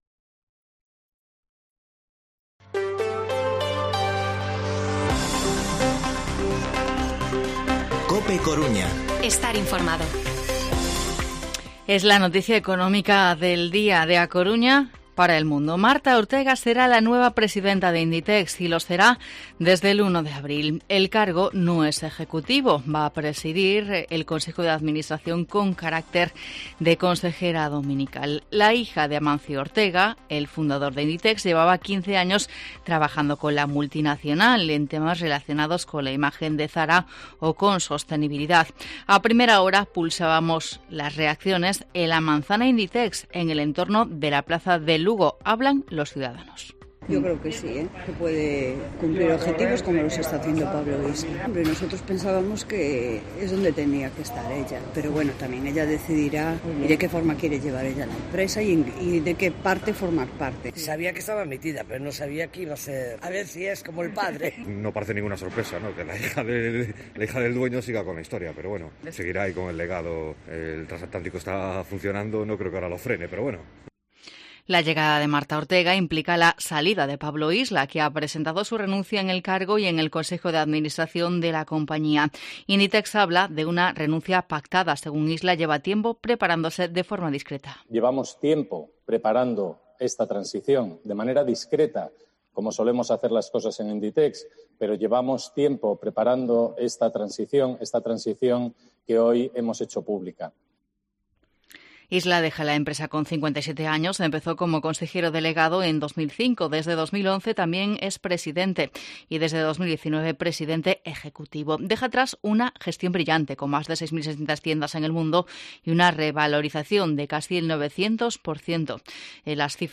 Informativo Mediodía COPE Coruña martes, 30 de noviembre de 2021 14:20-14:30